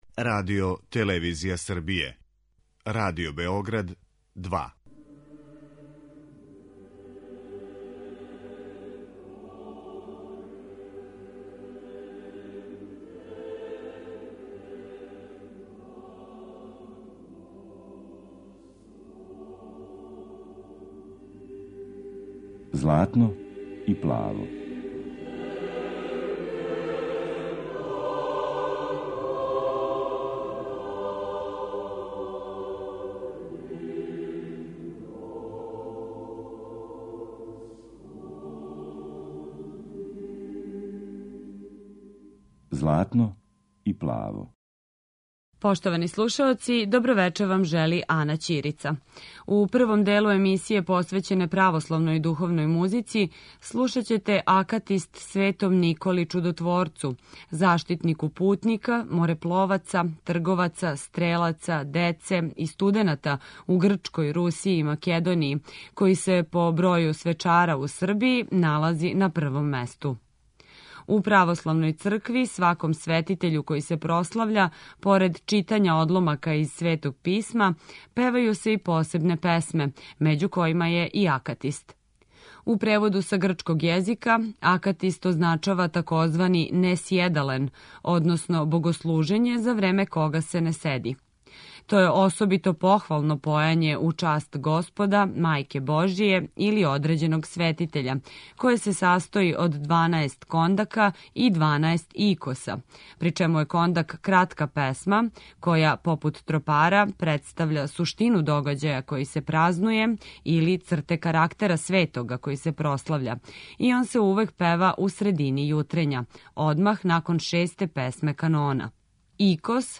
Eмисија посвећена православној духовној музици
У другом делу емитоваћемо одломке из Литургије Светог Јована Златоустог, Стевана Стојановића Мокрањца, у обради коју је за дечји хор приредио Војислав Илић. Двогласна обрада посвећена је хору "Колибри"